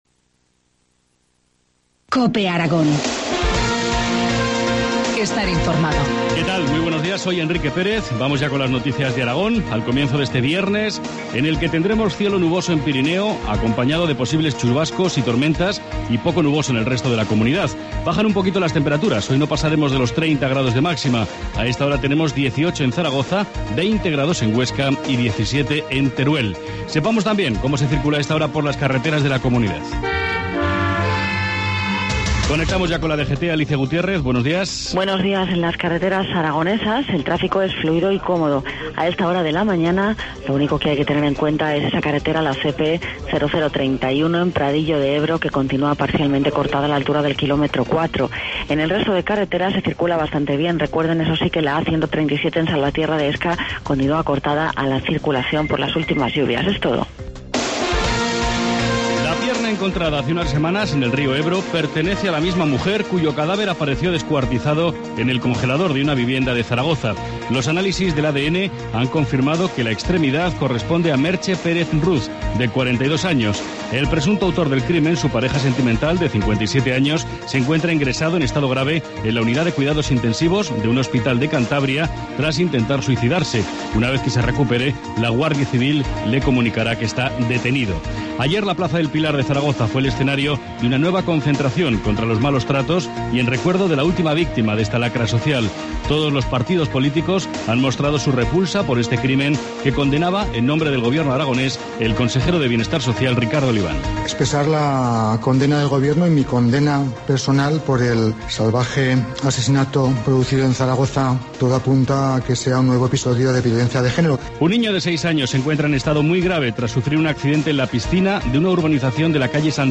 Informativo matinal, 14 junio, 7,25 horas